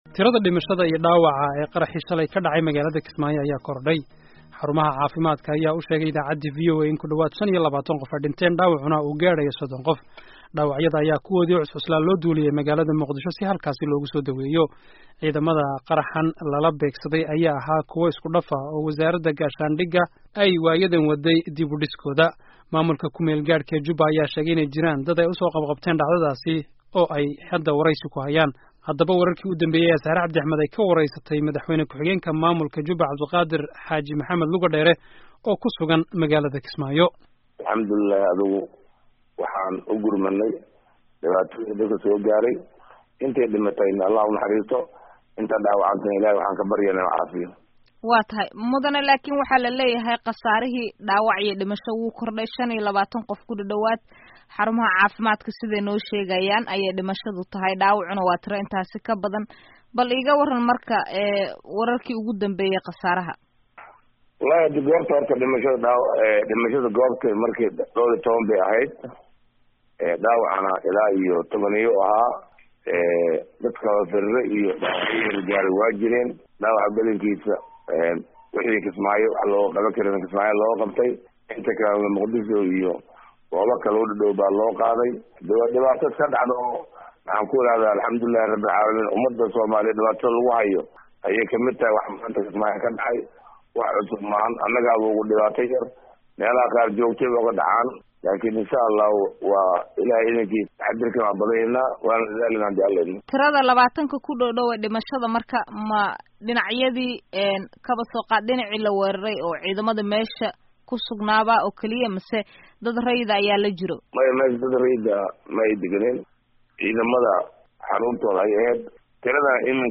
Dhageyso Wareysiga Qaraxa Kismaayo